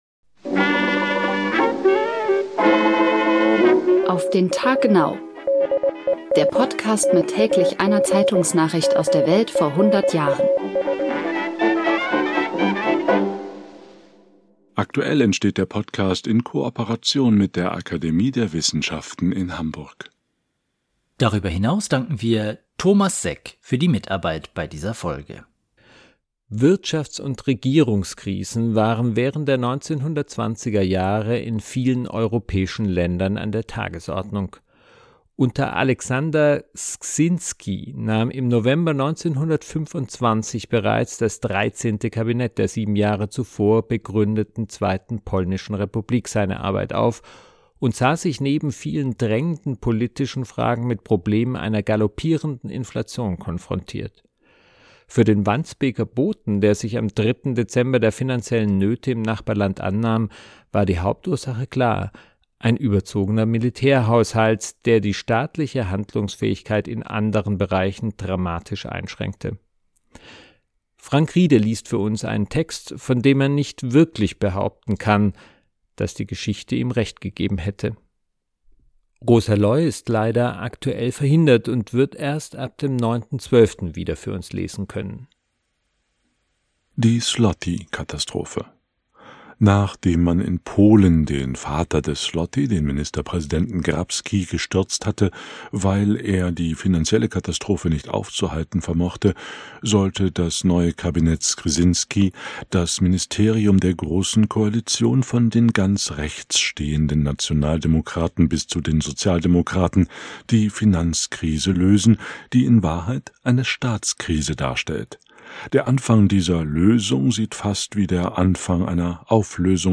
liest für uns einen Text